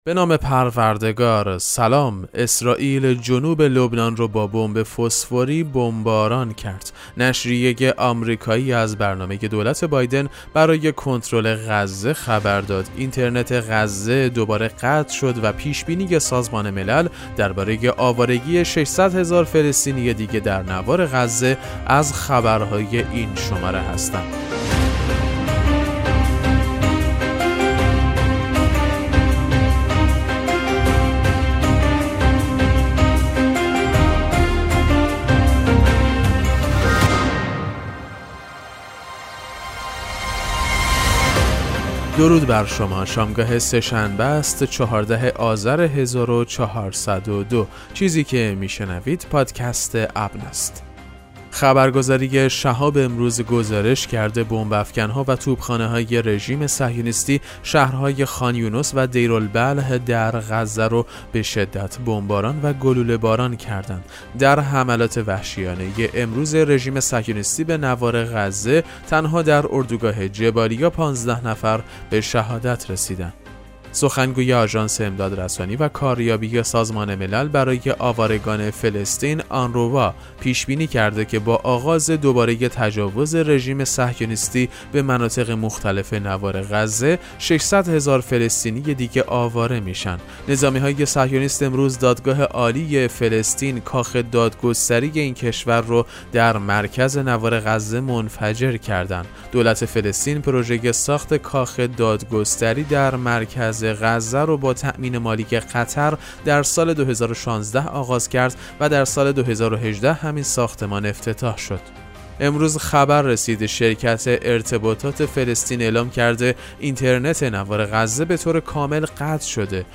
پادکست مهم‌ترین اخبار ابنا فارسی ــ 14 آذر 1402